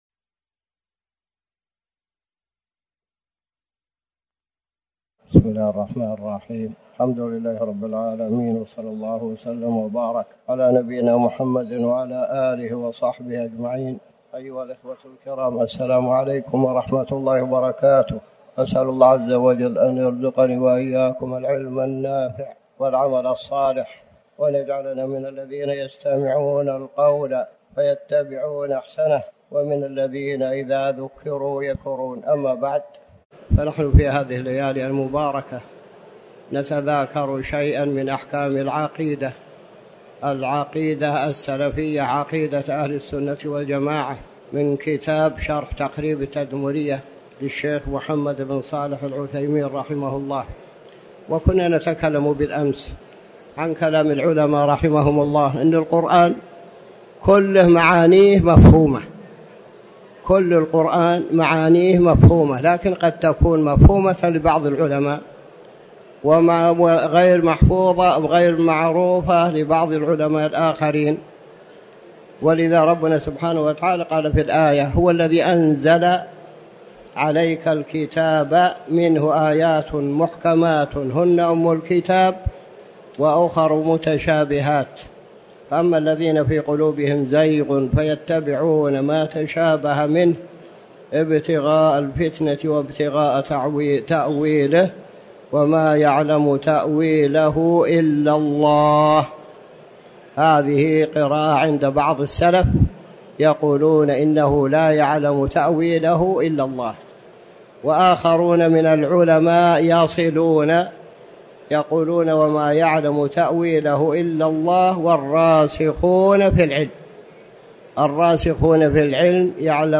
تاريخ النشر ٣ ربيع الثاني ١٤٤٠ هـ المكان: المسجد الحرام الشيخ